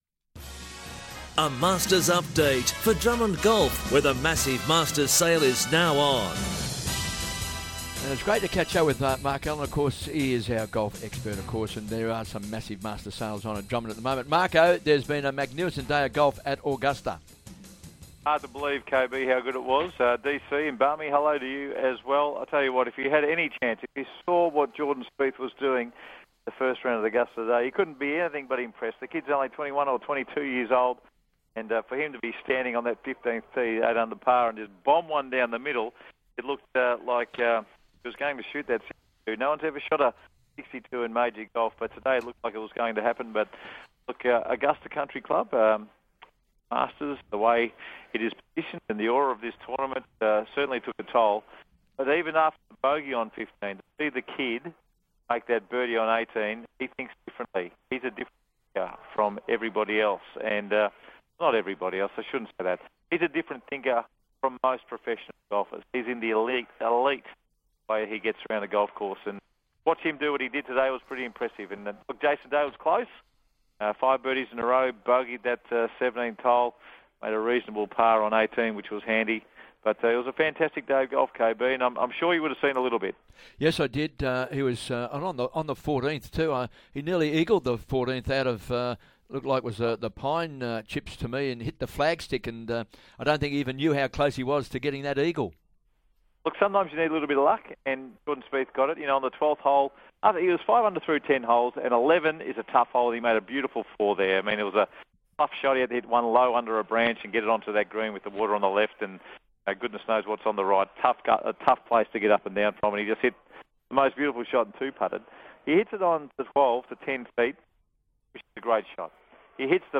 calls into Hungry For Sport for a Masters Update